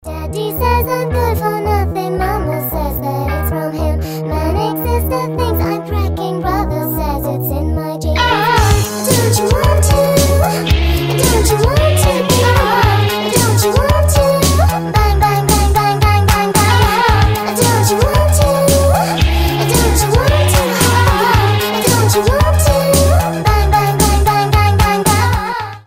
• Качество: 320, Stereo
милые
Synth Pop
детский голос
alternative
ремиксы